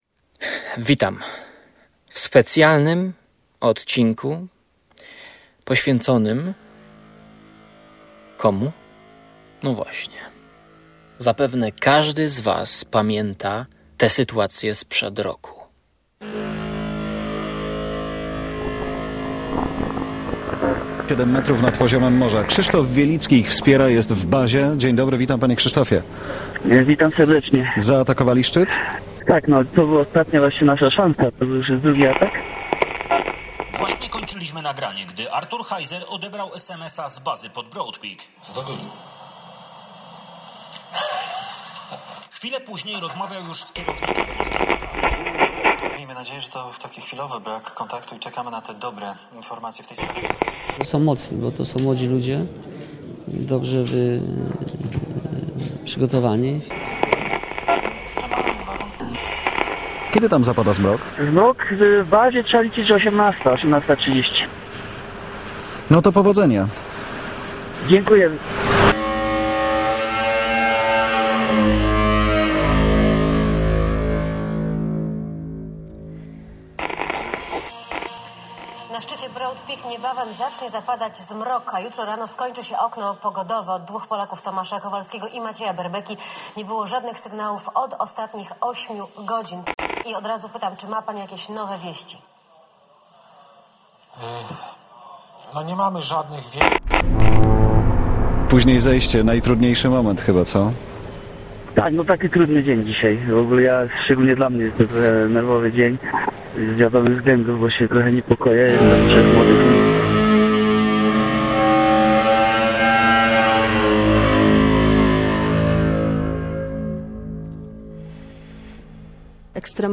Przepraszam za błędy w grze na gitarze i złą jakość.
[polecam słuchać na słuchawkach] Opis